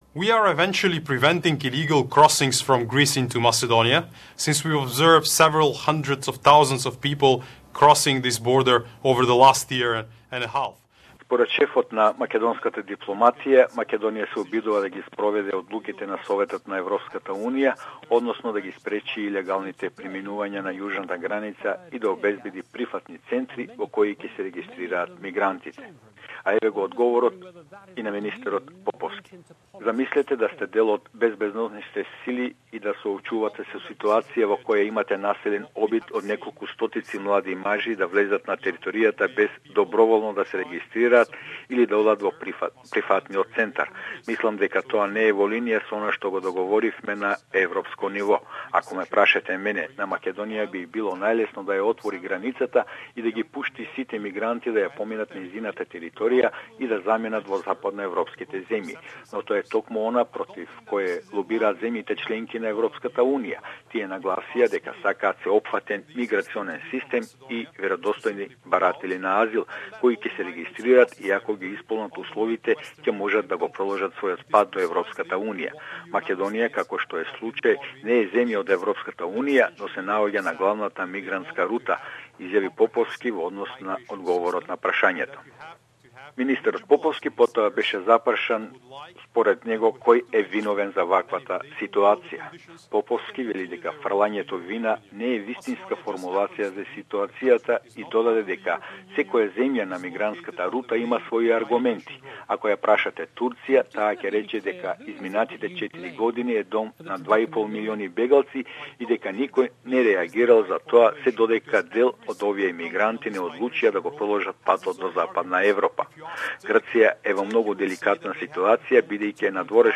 Macedonian Minister for Foreign Affairs, Nikola Poposki, interviewed on BBC's News night Source: BBC Youtube News night